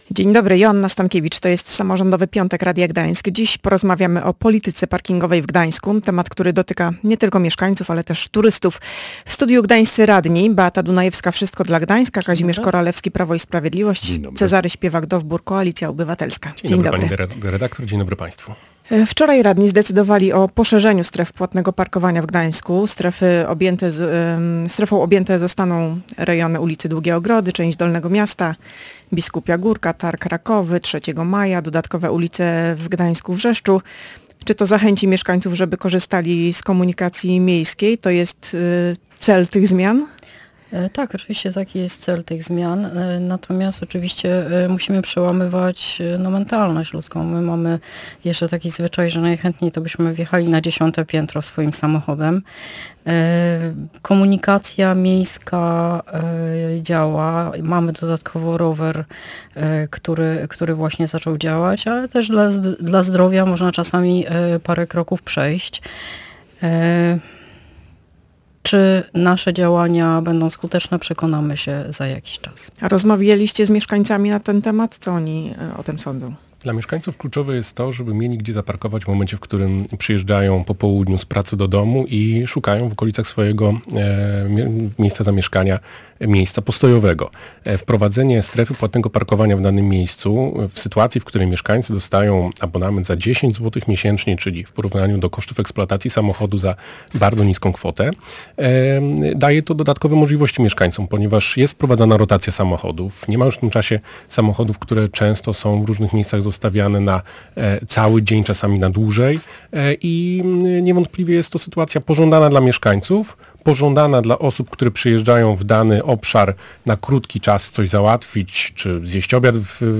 Zmiany komentują radni w Samorządowym Piątku